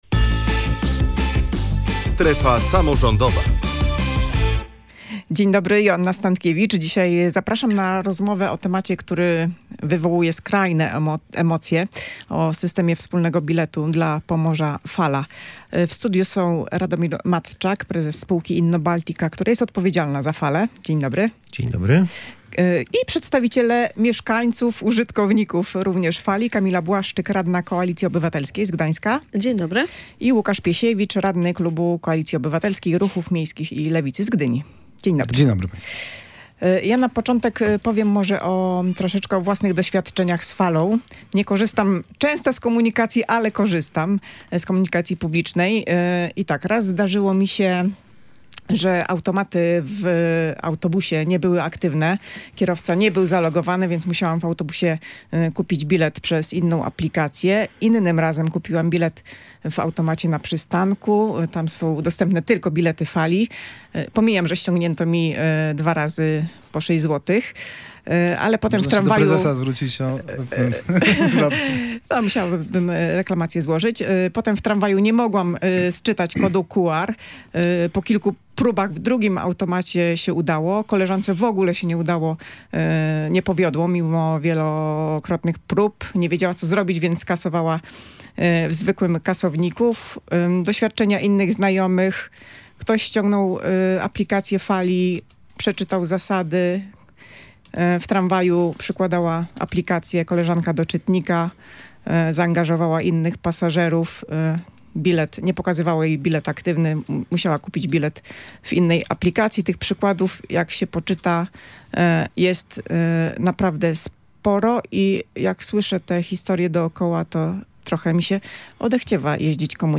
Jeszcze w lipcu ma zostać ogłoszony przetarg na obsługę informatyczną systemu Fala. Poprzedni został unieważniony, bo wpłynęła tylko jedna oferta i była o 100 milionów złotych wyższa niż spółka Innobaltica chciała przeznaczyć na ten cel. Między innymi o tym rozmawialiśmy w „Strefie Samorządowej”.